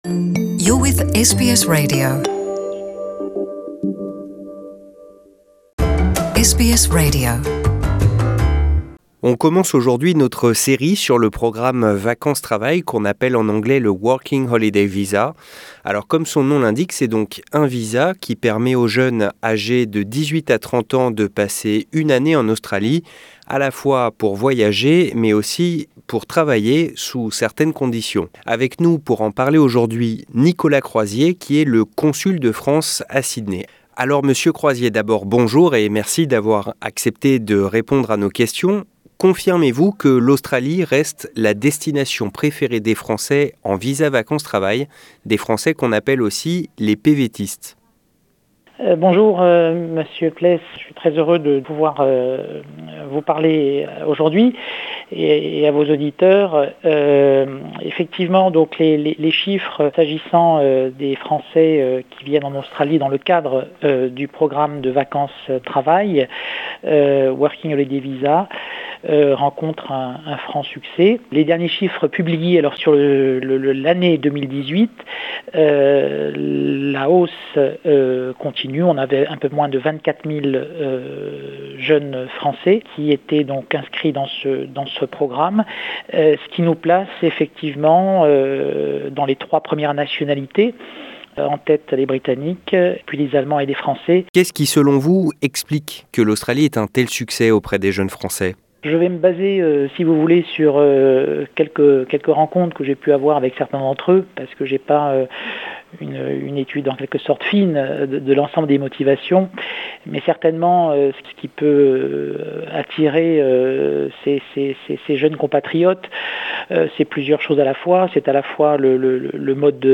Notre entretien avec Nicolas Croizier, l'ancien consul de France à Sydney, qui distille ses conseils pour bien réussir son année en Australie dans le cadre du programme vacances travail.